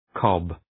Προφορά
{kɒb}
cob.mp3